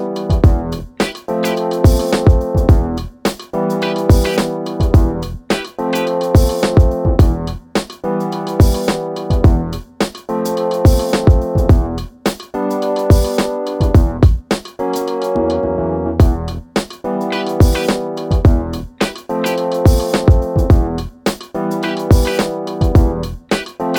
Duet Version Pop (1990s) 3:50 Buy £1.50